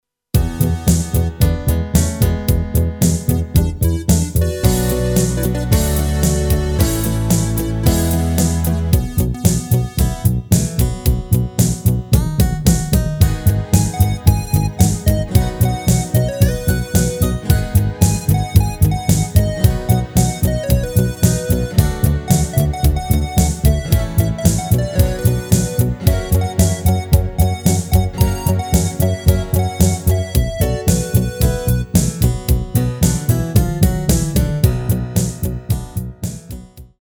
Rubrika: Folk, Country